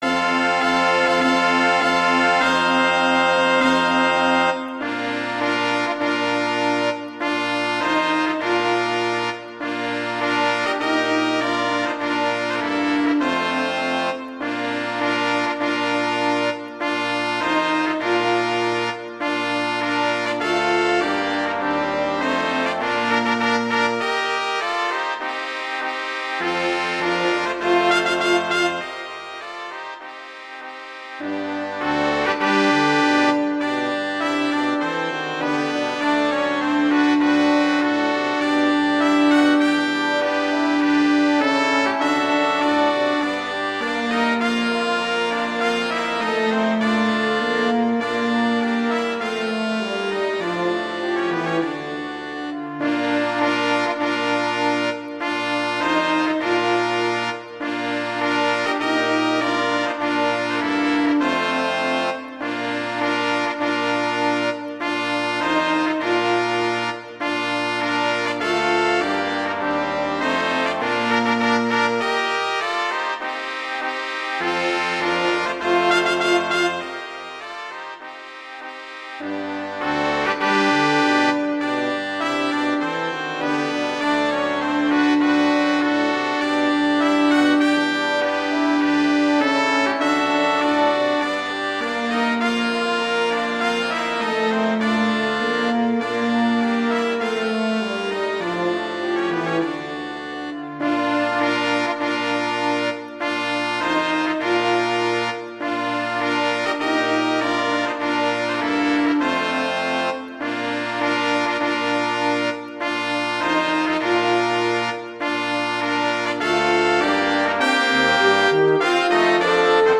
Instrumentation: brass quintet
arrangements for brass quintet
wedding, traditional, classical, festival, love, french